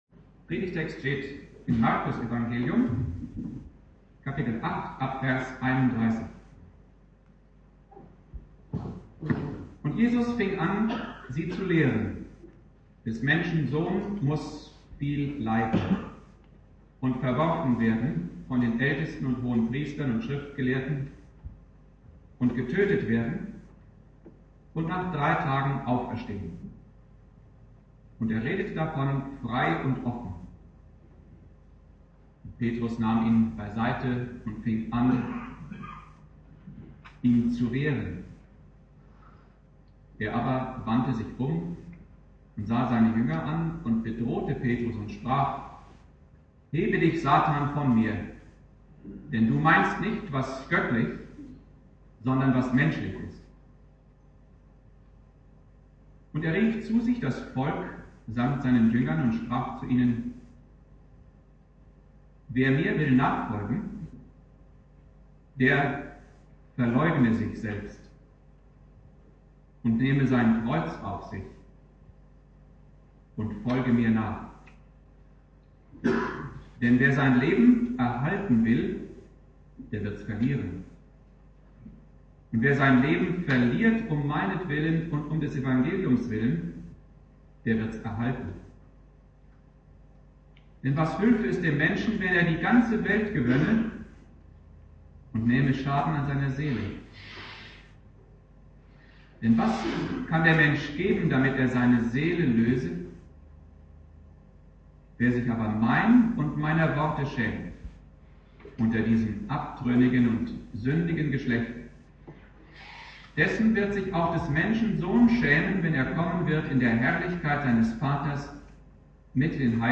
Predigt
(mit Außenmikrofon aufgenommen) Bibeltext: Markus 8,31-38 Dauer